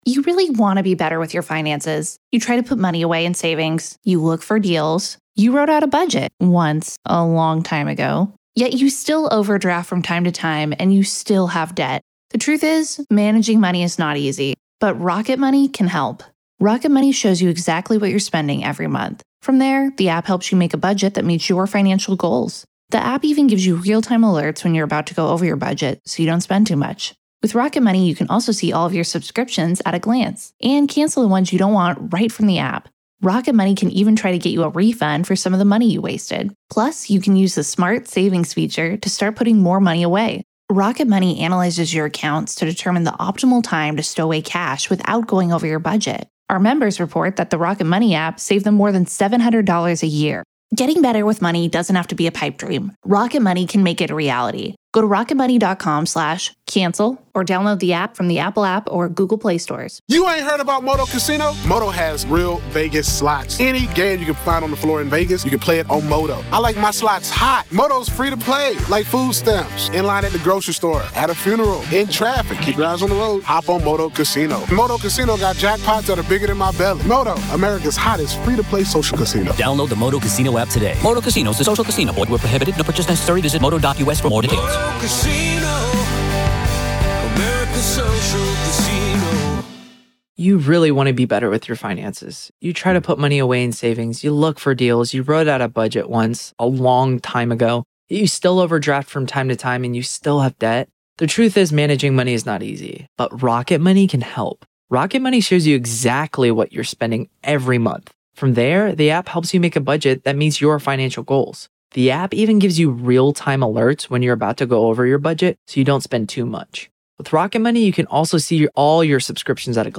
Jury Instructions
LIVE COURTROOM COVERAGE — NO COMMENTARY